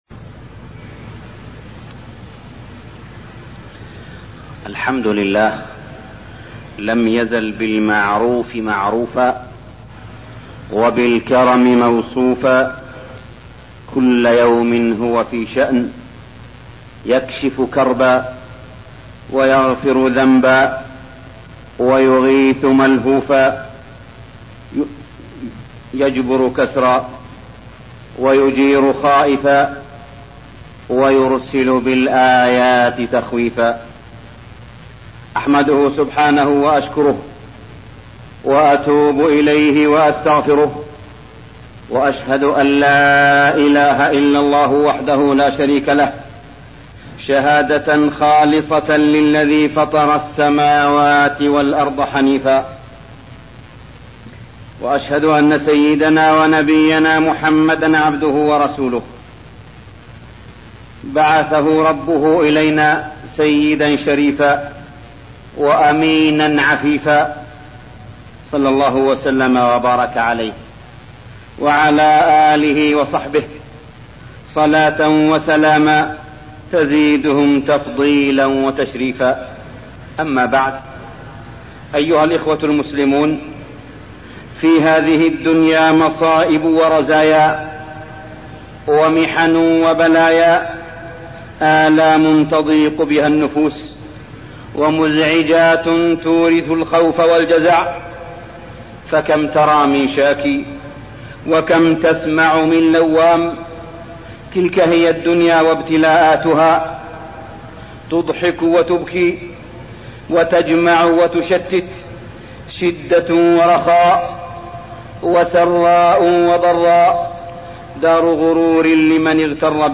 تاريخ النشر ٤ صفر ١٤١١ هـ المكان: المسجد الحرام الشيخ: معالي الشيخ أ.د. صالح بن عبدالله بن حميد معالي الشيخ أ.د. صالح بن عبدالله بن حميد محنة أهل الكويت